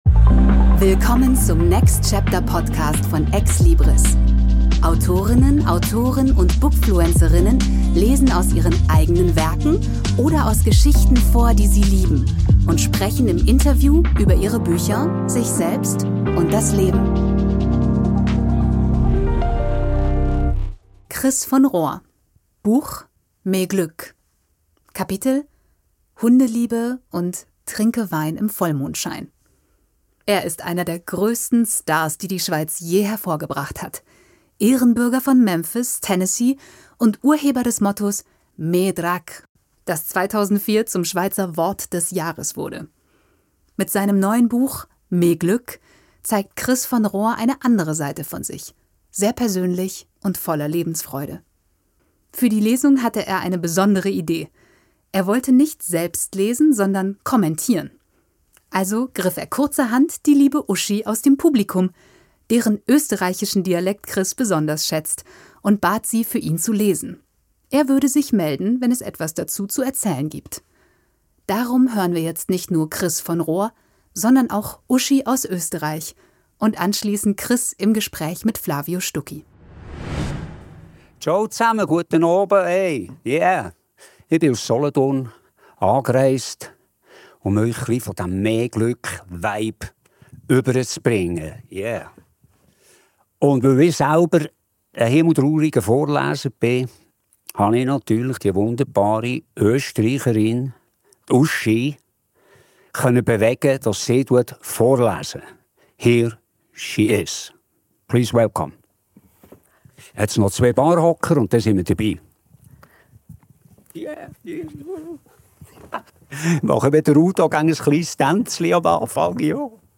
Für die Lesung hatte er eine besondere Idee: Er wollte nicht selbst lesen, sondern kommentieren.